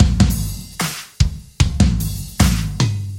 舞蹈节拍循环2
描述：舞蹈循环的鼓声而已
Tag: 150 bpm Rock Loops Drum Loops 600.04 KB wav Key : Unknown